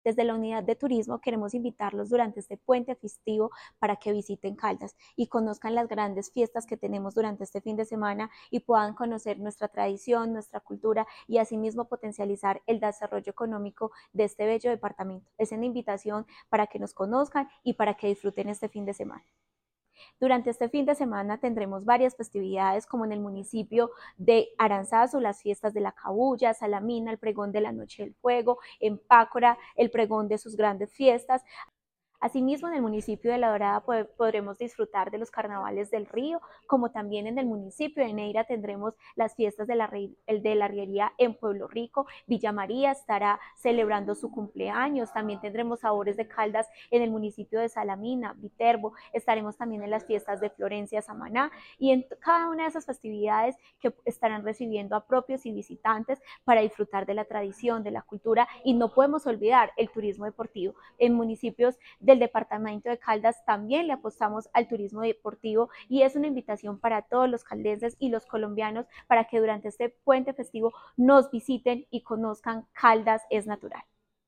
Tania-Echverry-Rivera-secretaria-de-Desarrollo-Empleo-e-Innovacion-de-Caldas.mp3